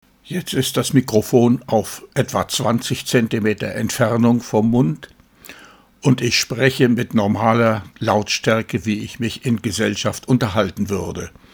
Um den Einfluss des Abstands zwischen Mund und Mikrofon zu zeigen, habe ich zwei kurze Aufnahmen gemacht.
Mikrofon etwa 20 cm vom Mund entfernt (mp3)
Mikro_fern.mp3